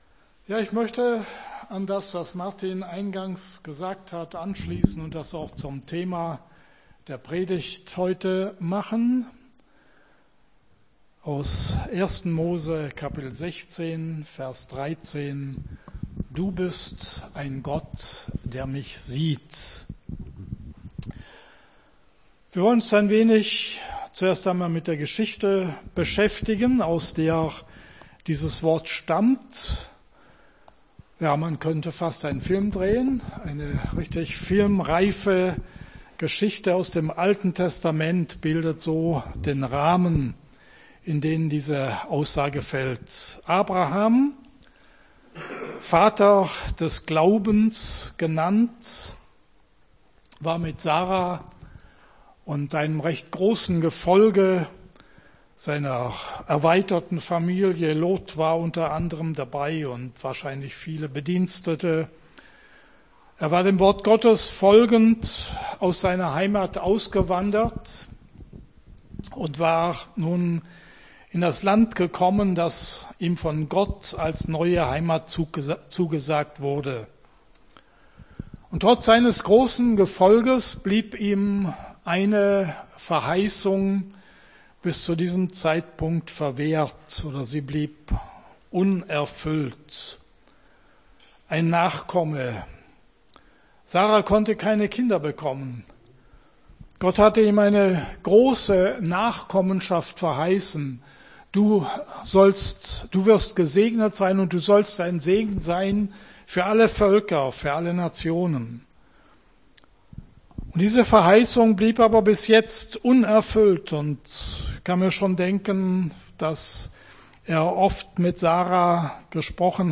Gottesdienst 08.01.23 - FCG Hagen